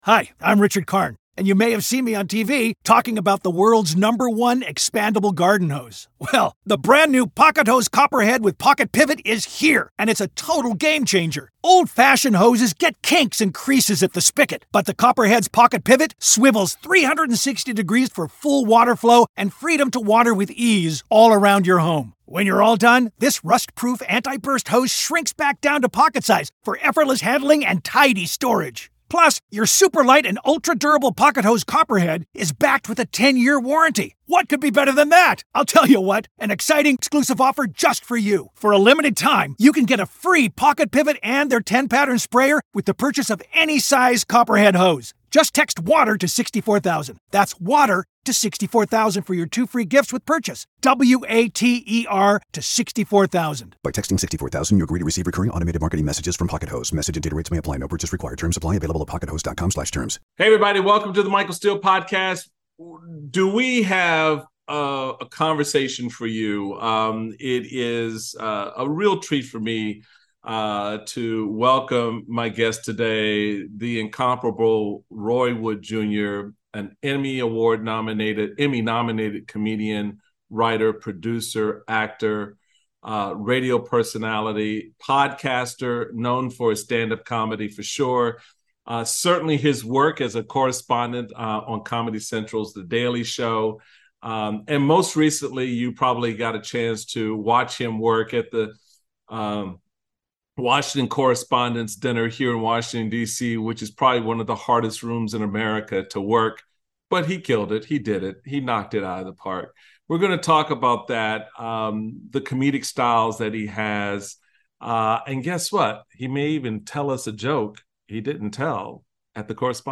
Comedian Roy Wood Jr. joins The Michael Steele Podcast. Roy talks about how he approaches a roast, what being considered as the next host of The Daily Show means to him and what it's like doing stand up in the age of cancel culture. Roy also discusses hosting the White House Correspondents' Dinner, the jokes that didn't make it, and what he was up against performing for the audience.